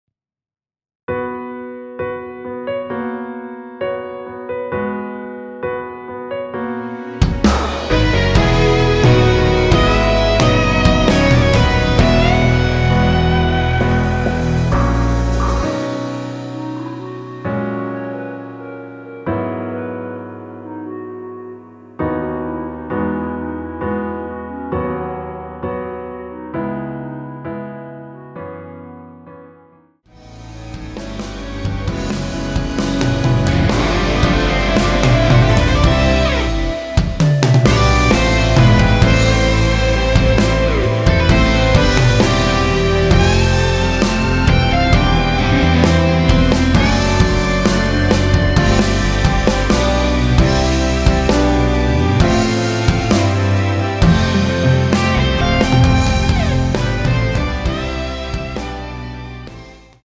원키에서(-2)내린 멜로디 포함된 MR입니다.(미리듣기 확인)
앞부분30초, 뒷부분30초씩 편집해서 올려 드리고 있습니다.
중간에 음이 끈어지고 다시 나오는 이유는